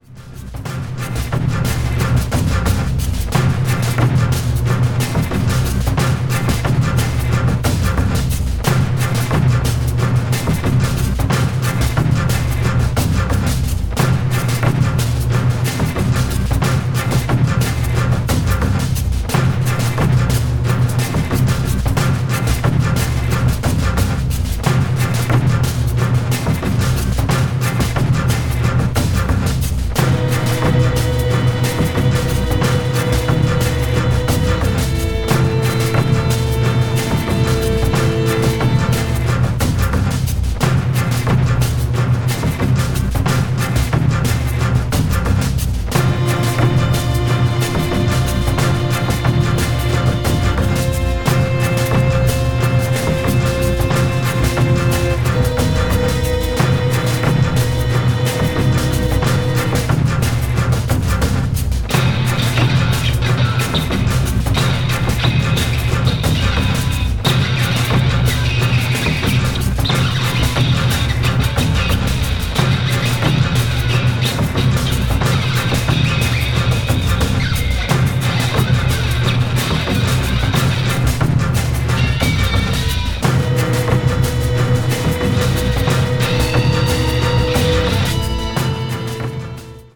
media : EX+/EX+(some slightly noises.)